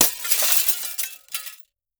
GLASS_Window_Break_01_mono.wav